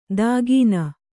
♪ dāgīna